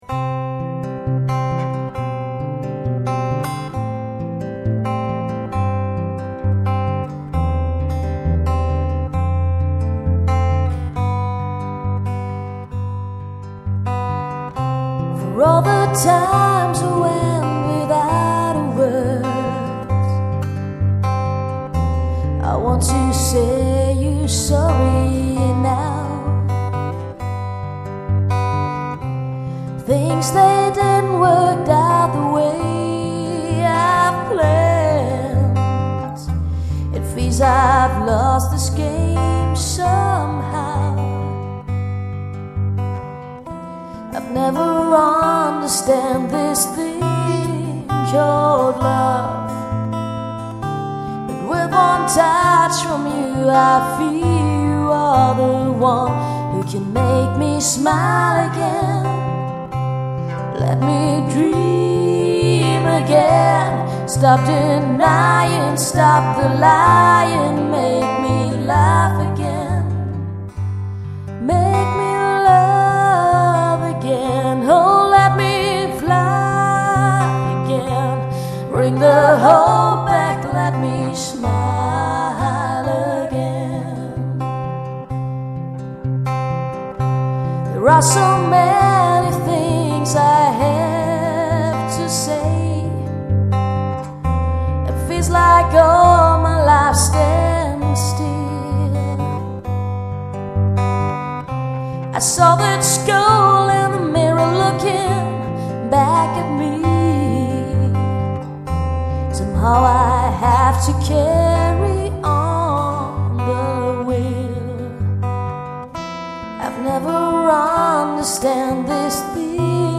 der bei diesem Song die Gitarre gespielt hat